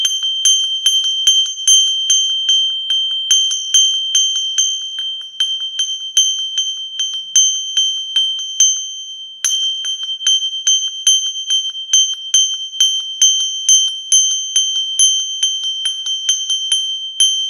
Domový zvonček liatinový
Rozmery: š 10,5 x v 22 x h 13 cm
Materiál: liatina